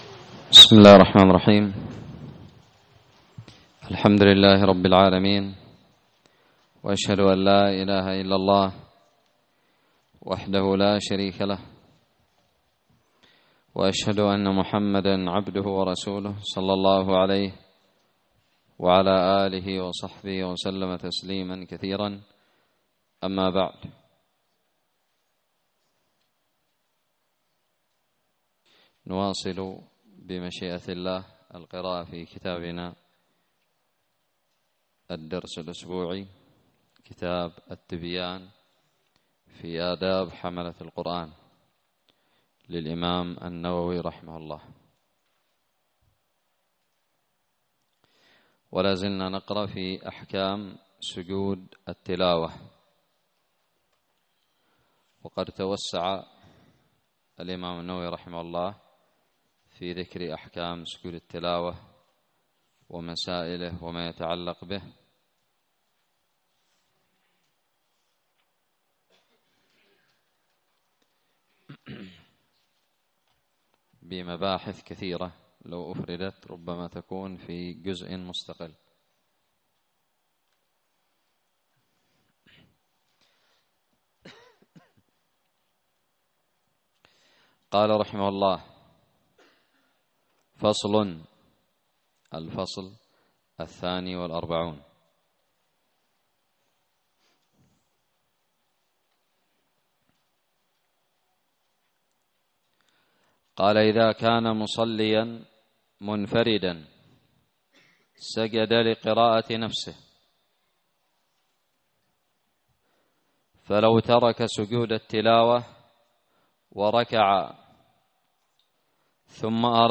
الدرس الثامن والعشرون من شرح كتاب التبيان في آداب حملة القرآن
ألقيت بدار الحديث السلفية للعلوم الشرعية بالضالع